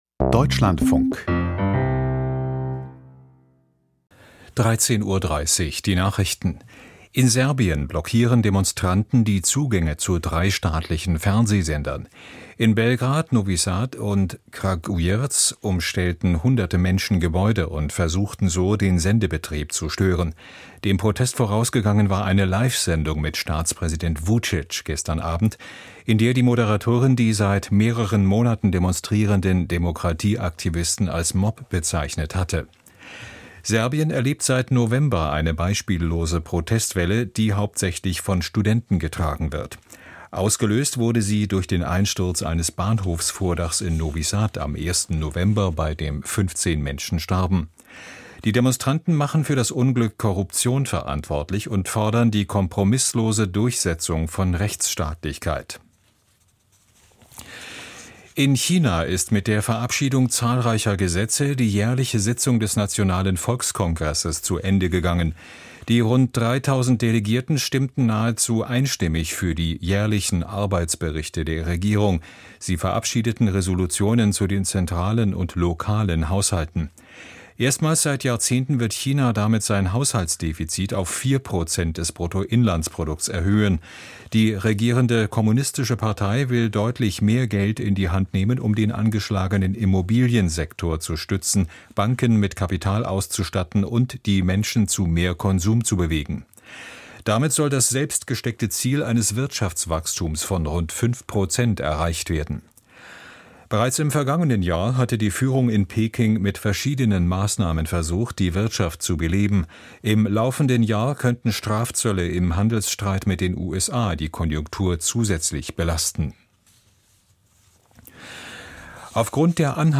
Die Deutschlandfunk-Nachrichten vom 11.03.2025, 13:30 Uhr